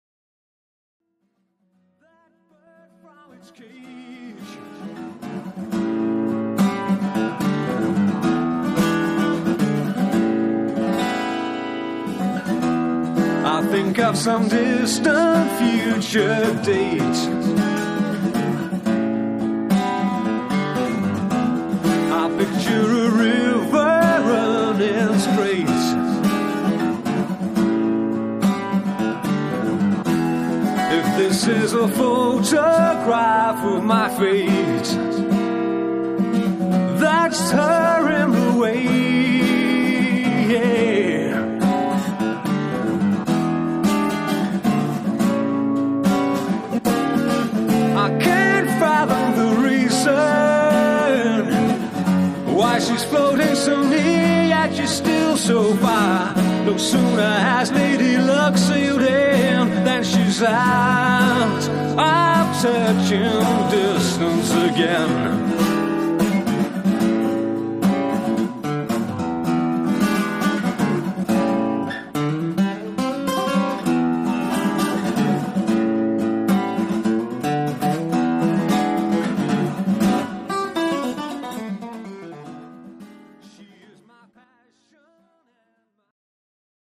fully arranged songs